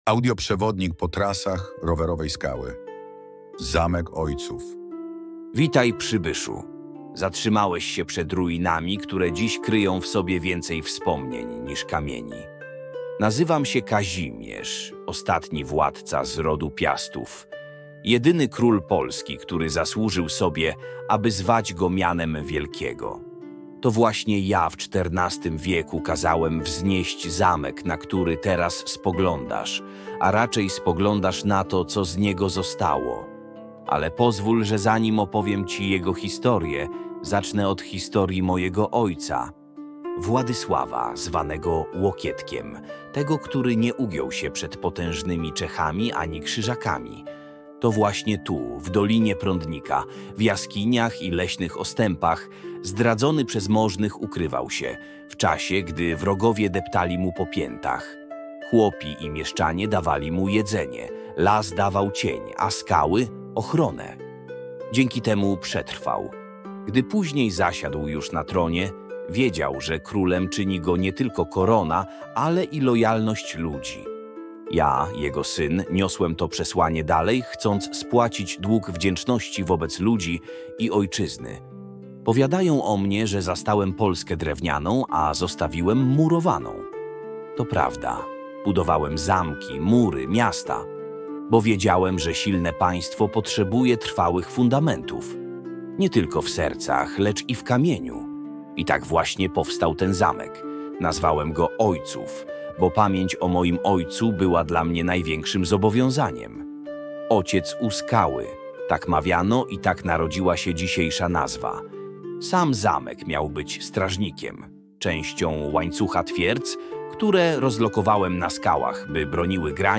audiobook_Zamek-w-Ojcowie-mfo30rqx.mp3